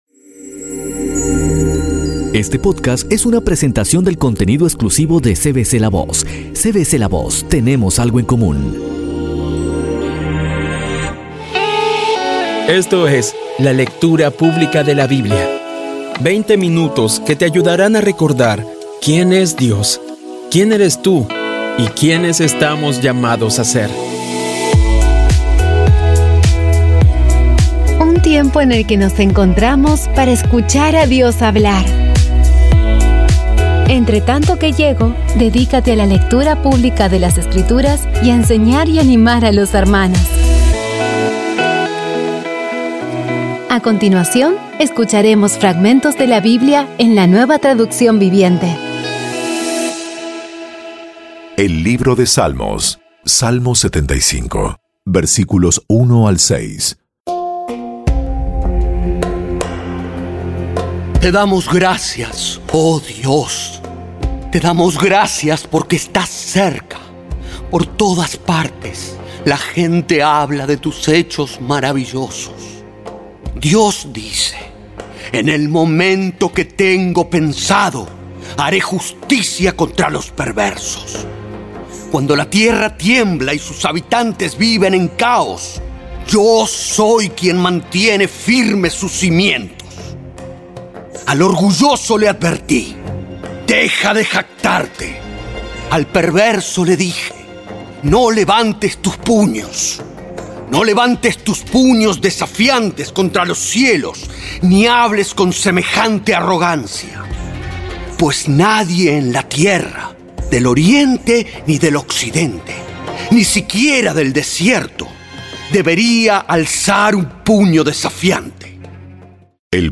Audio Biblia Dramatizada Episodio 175
Poco a poco y con las maravillosas voces actuadas de los protagonistas vas degustando las palabras de esa guía que Dios nos dio.